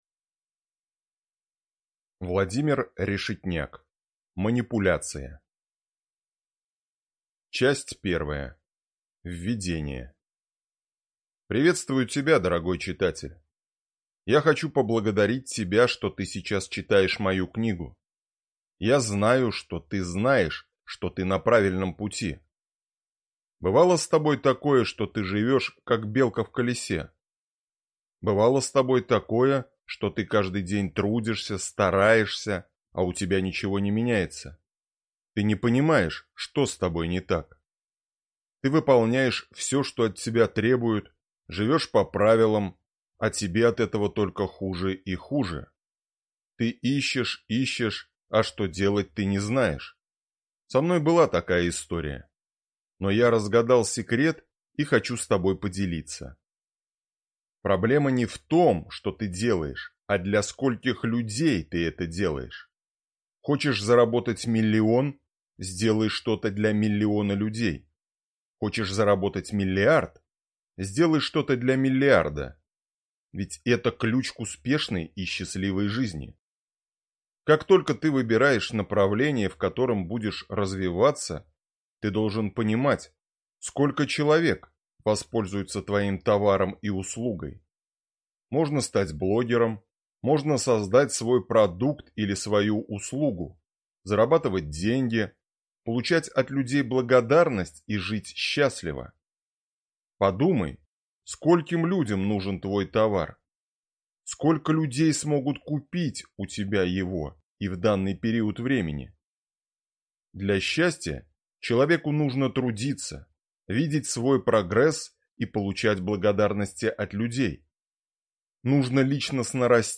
Аудиокнига Манипуляция | Библиотека аудиокниг